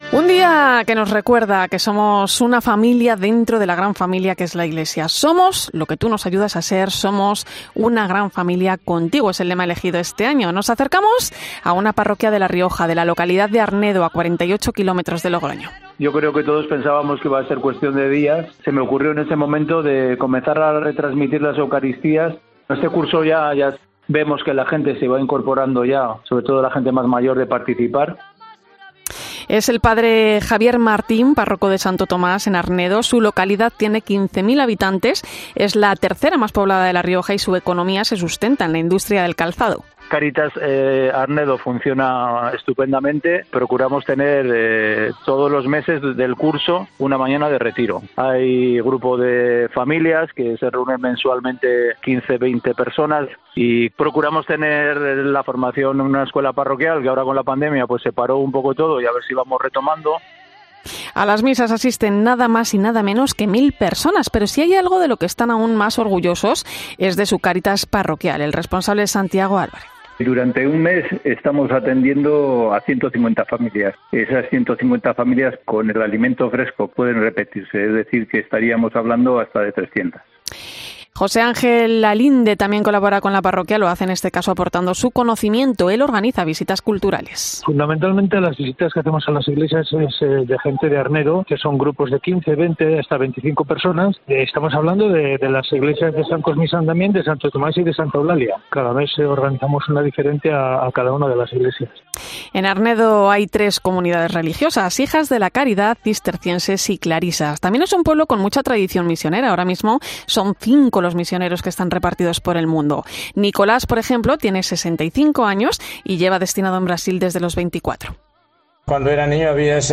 AUDIO: Hablamos con los fieles de este pueblo riojano con motivo del Día de la Iglesia Diocesana